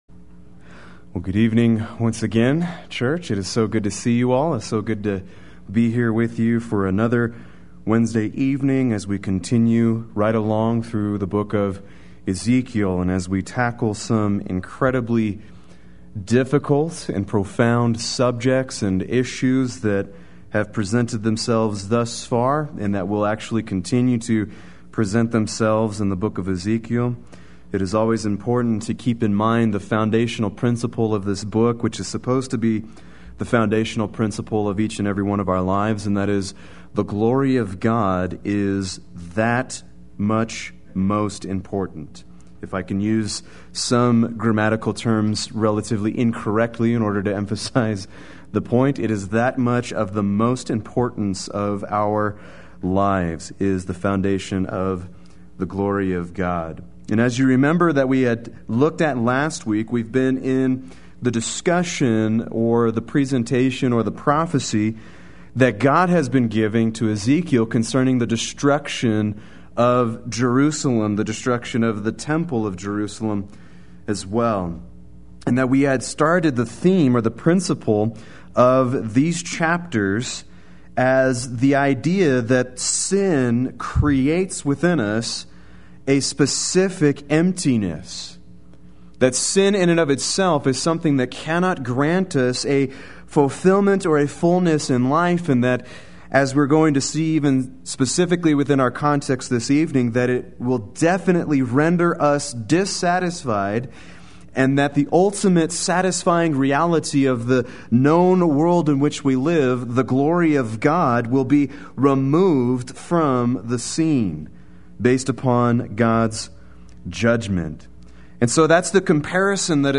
Play Sermon Get HCF Teaching Automatically.
Chapters 7:10-8:16 Wednesday Worship